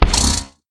Minecraft Version Minecraft Version latest Latest Release | Latest Snapshot latest / assets / minecraft / sounds / mob / horse / skeleton / hit3.ogg Compare With Compare With Latest Release | Latest Snapshot